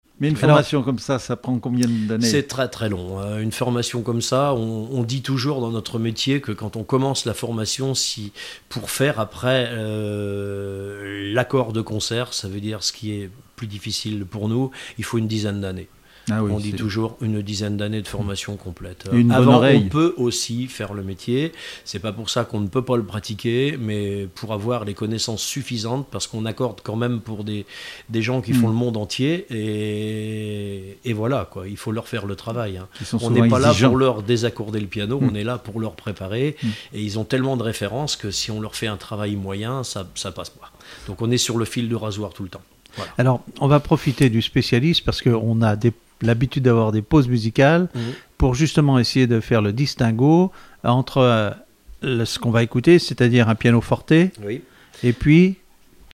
Emissions de la radio RCF Vendée
Témoignage sur le métier de facteur accordeur de piano